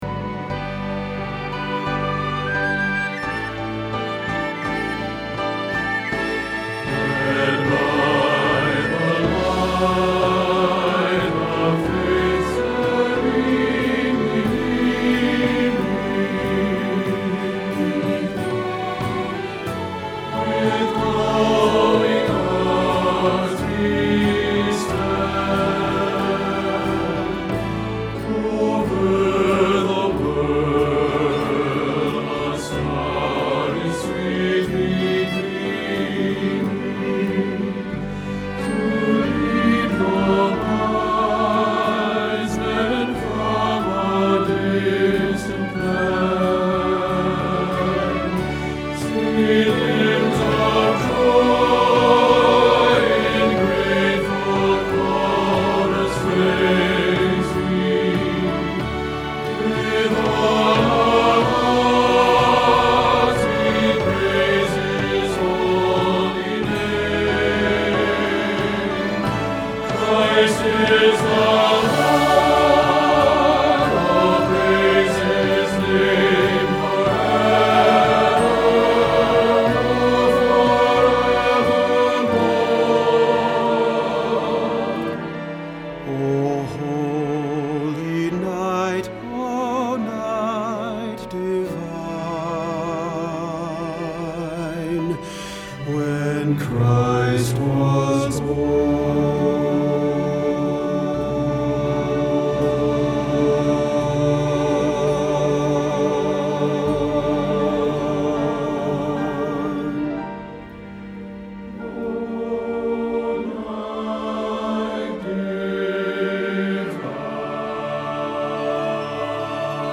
O Holy Night – Bass – Hilltop Choir
O Holy Night – Bass Hilltop Choir